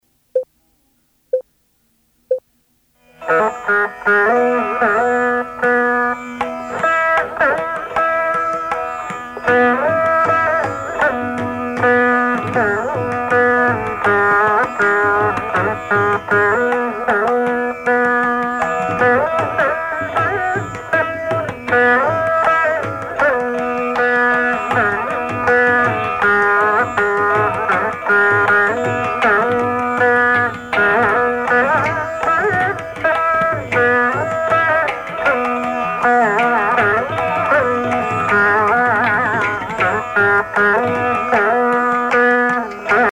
Musique carnatique
Pièce musicale inédite